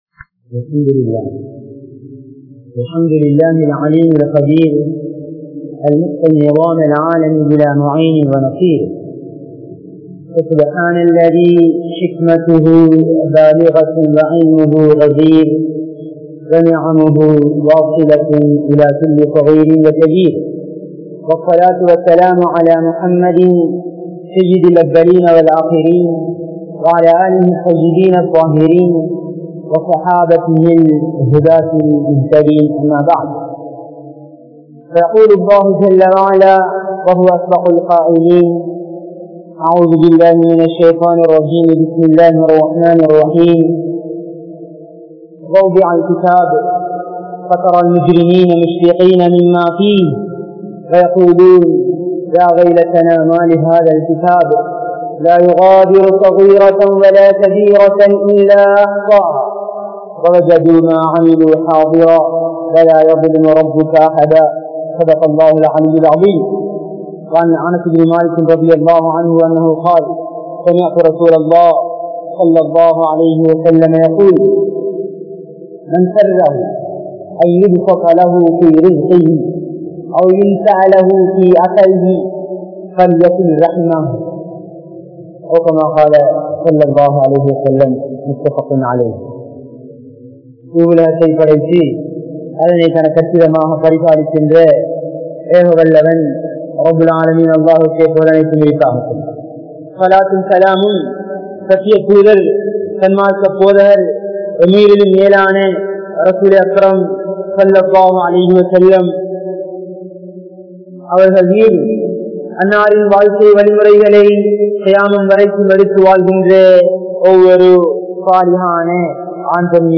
Islamiya Paarvaiel New Year (இஸ்லாமிய பார்வையில் புதுவருடம்) | Audio Bayans | All Ceylon Muslim Youth Community | Addalaichenai